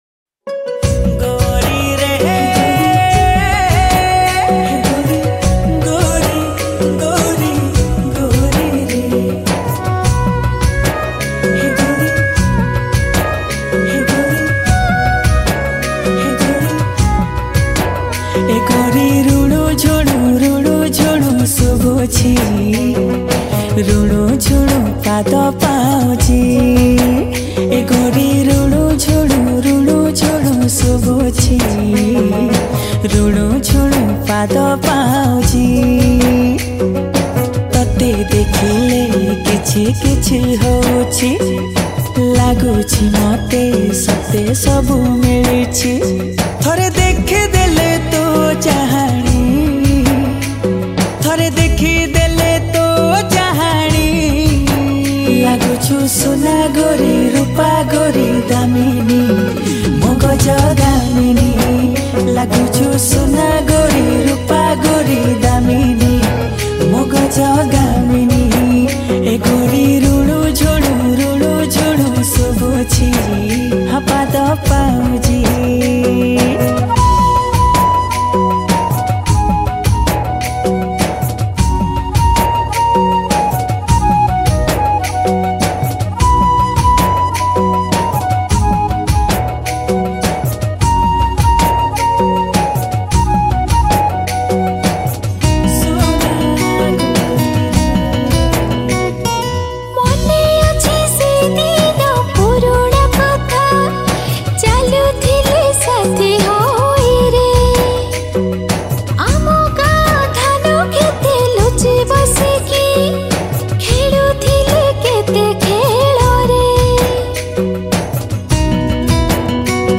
Keaboard